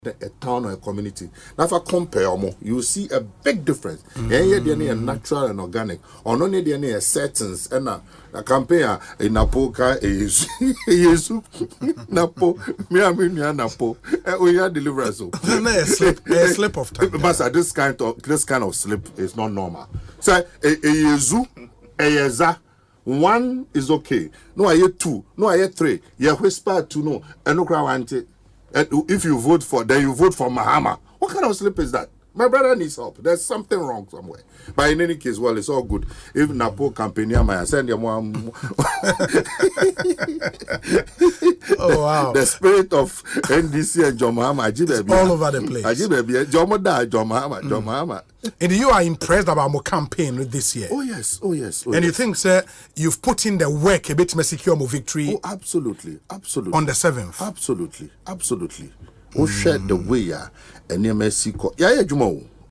Speaking on Asempa FM’s Ekosii Sen show, Mr. Afriyie Ankrah said the NPP is simply obsessed with John Mahama.